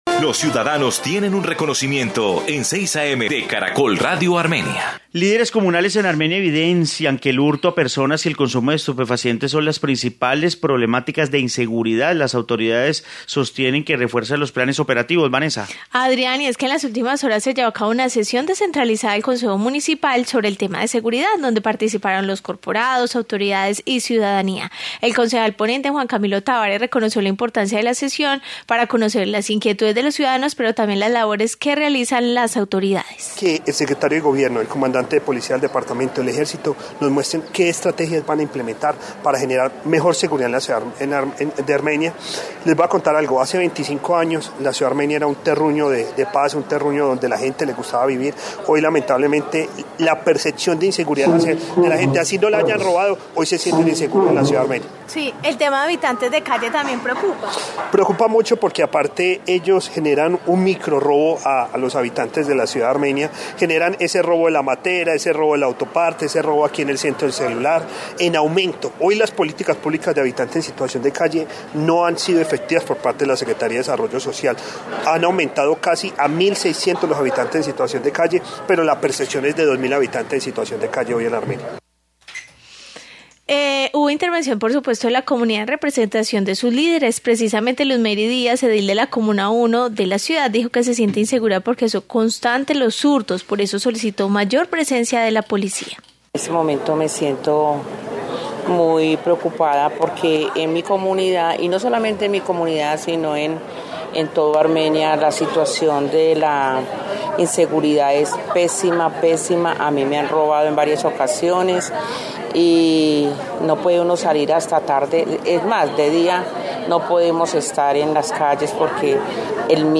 Informe sobre debate de seguridad en Armenia
En las últimas horas se llevó a cabo una sesión descentralizada del Concejo Municipal sobre el tema de seguridad donde participaron los corporados, autoridades y ciudadanía.